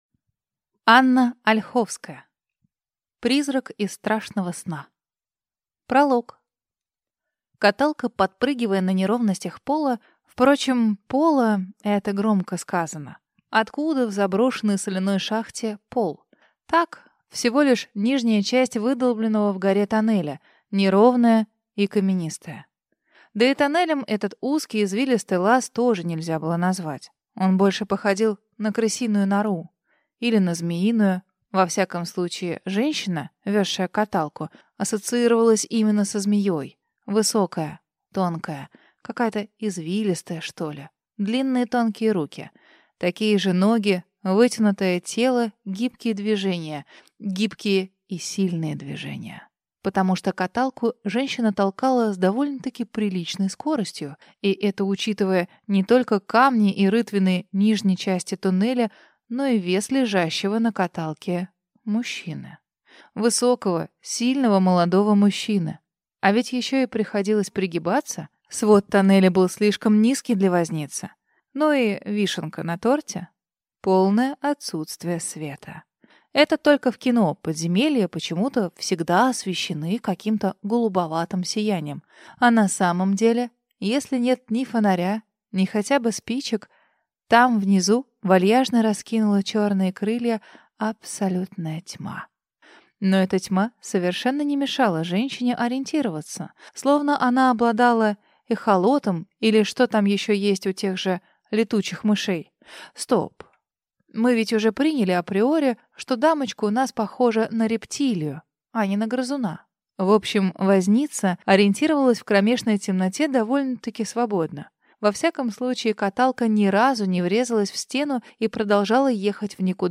Аудиокнига Призрак из страшного сна | Библиотека аудиокниг